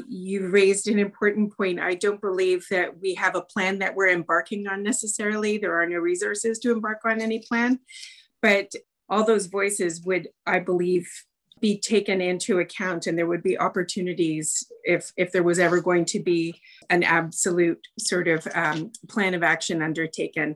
Prince Edward County council heard a presentation on a master heritage plan for the Picton fairgrounds at Tuesday’s council meeting.
Councillor Kate MacNaughton assured them that the master plan is not a solid plan but an idea.
macnaughton-march-8-2021.wav